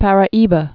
(părə-ēbə, pärä-ēbä) also Paraíba do Sul (d sl)